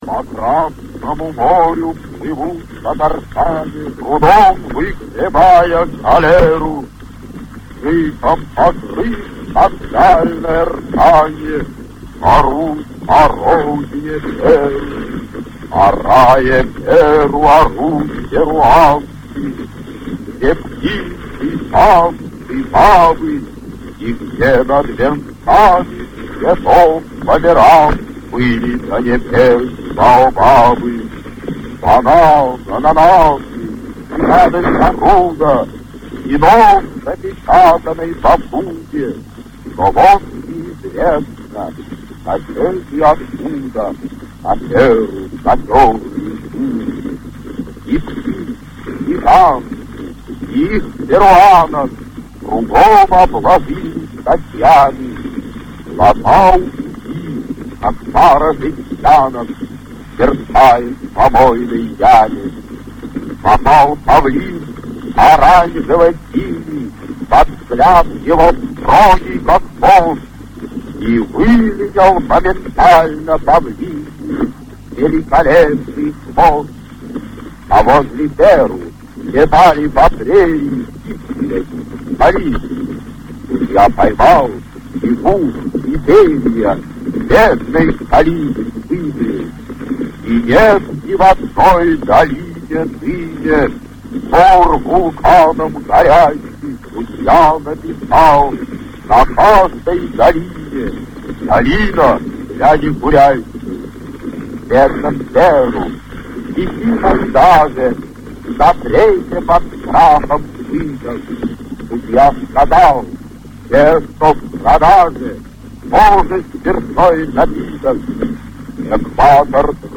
11. «Владимир Маяковский – Гимн судье (читает сам Маяковский)» /